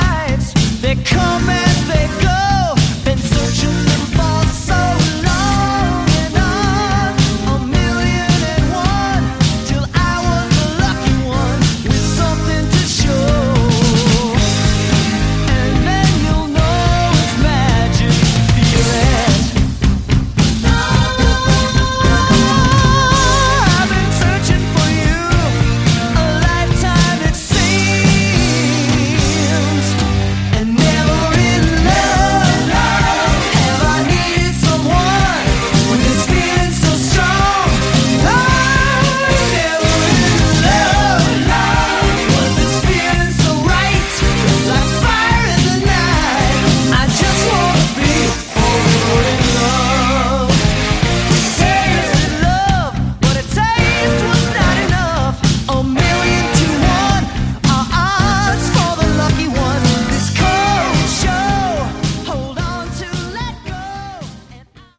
Category: Pomp AOR
lead vocals, keyboards
guitars, vocals
bass, vocals
drums